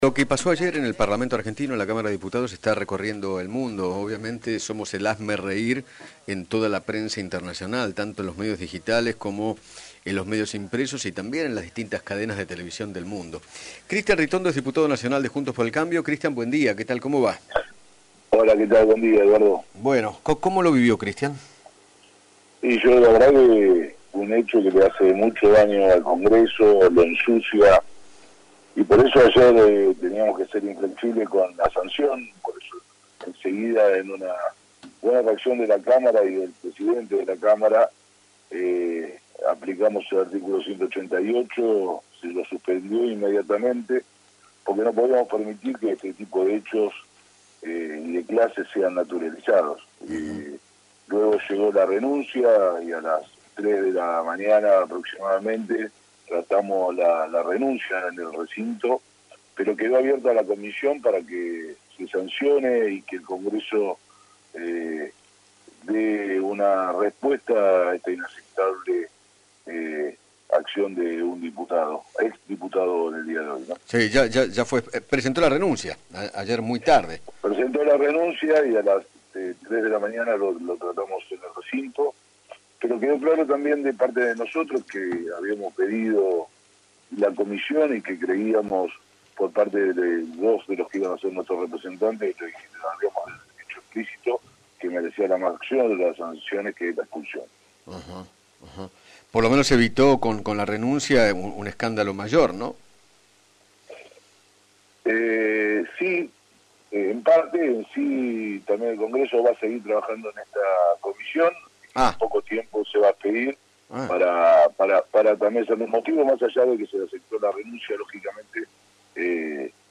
Cristian Ritondo, diputado nacional, dialogó con Eduardo Feinmann sobre el escándalo que protagonizó el ahora ex diputado Juan Ameri durante una sesión virtual de la Cámara de Diputados y señaló que “en la oposición creíamos que merecía la expulsión”.